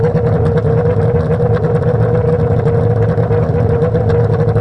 f1_v8_idle.wav